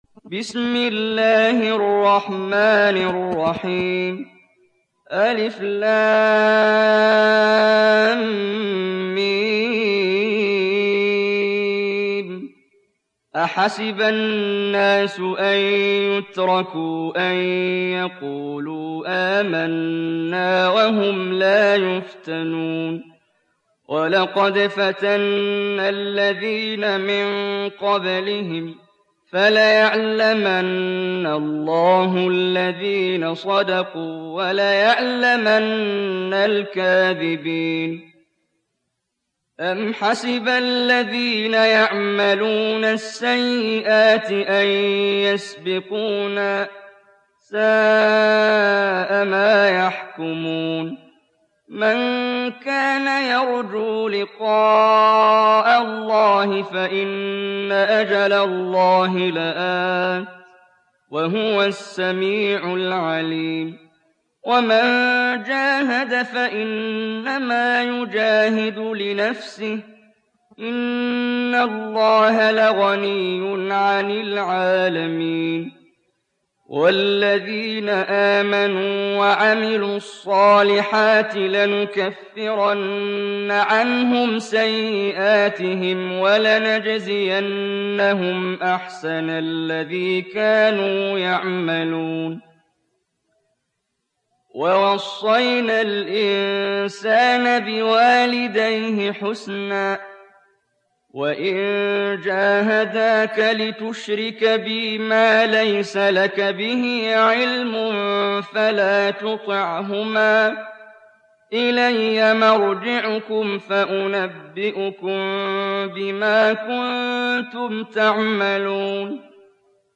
Sourate Al Ankabut mp3 Télécharger Muhammad Jibreel (Riwayat Hafs)